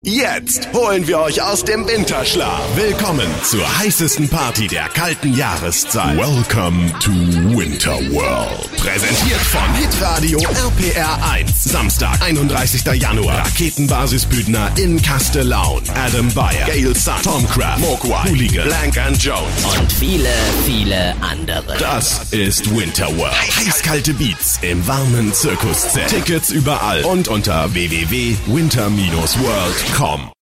Sprecher deutsch für Werbung, Off, Hörspiel, Hörbuch, etc.
Sprechprobe: eLearning (Muttersprache):
german voice over artist